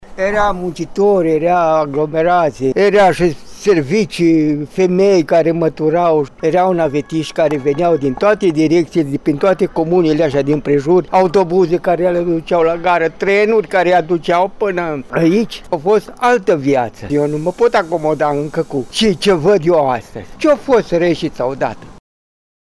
De la unul dintre reşiţeni, călător fidel, am aflat cum era pe vremuri: